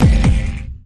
Appear_Scatter_Sound_elimin.mp3